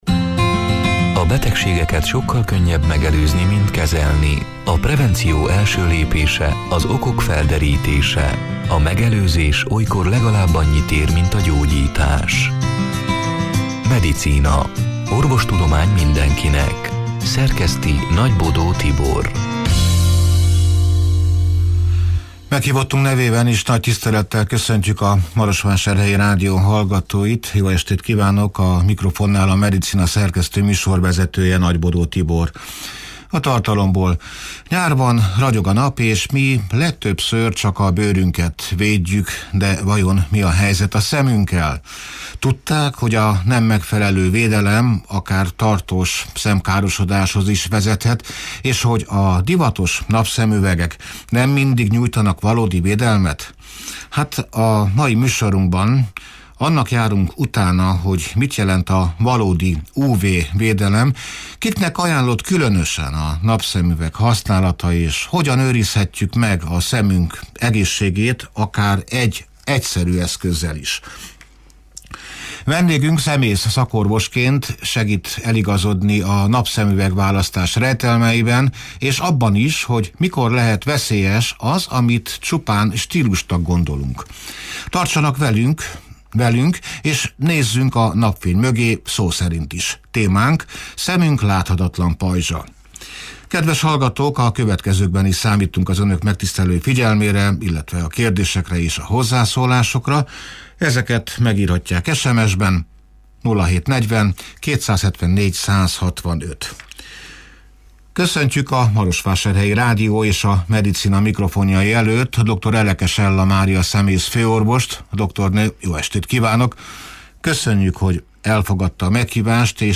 (elhangzott: 2025. június 25-én, szerdán este nyolc órától élőben)
A mai műsorunkban annak járunk utána, hogy mit jelent a valódi UV-védelem, kiknek ajánlott különösen a napszemüveg használata, és hogyan őrizhetjük meg szemünk egészségét akár egy egyszerű eszközzel is. Vendégünk, szemész szakorvosként segít eligazodni a napszemüvegválasztás rejtelmeiben, és abban is, hogy mikor lehet veszélyes, amit stílusnak gondolunk.